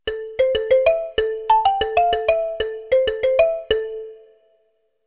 ringin.mp3